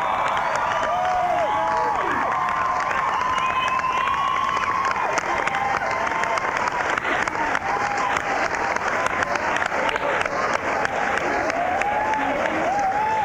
02. crowd (0:13)